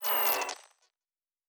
pgs/Assets/Audio/Sci-Fi Sounds/Electric/Device 3 Stop.wav at 7452e70b8c5ad2f7daae623e1a952eb18c9caab4
Device 3 Stop.wav